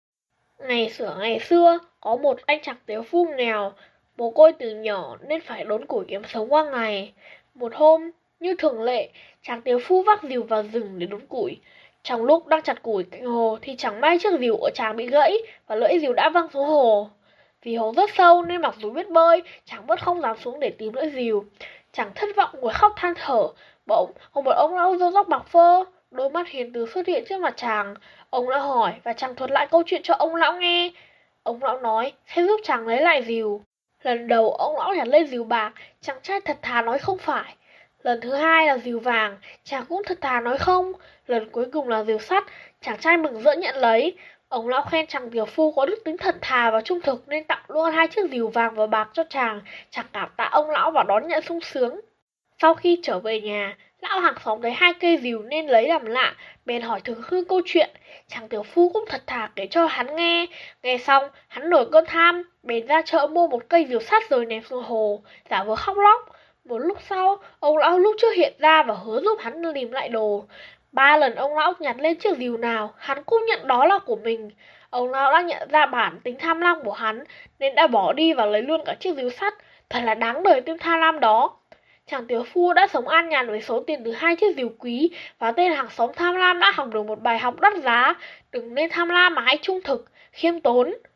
Sách nói | Truyện: Ba lưỡi rìu